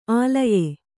♪ ālaye